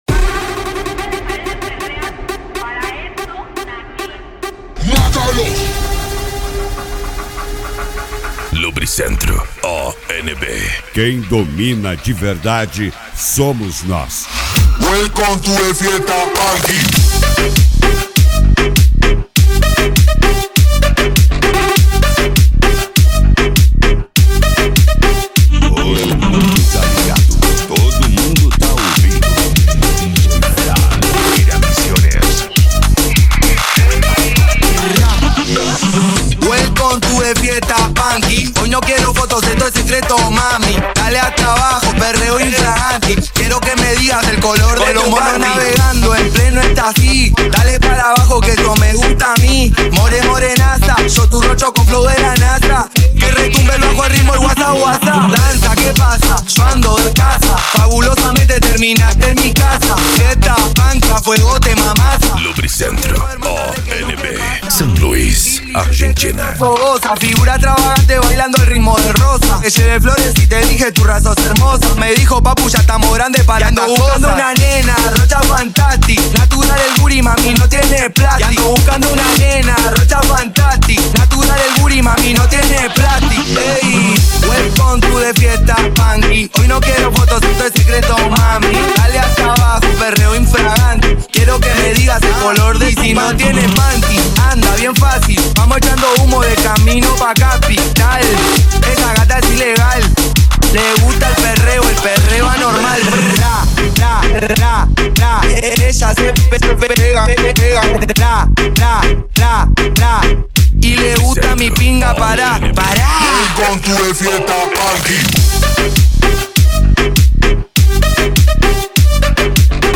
Cumbia
Funk
Remix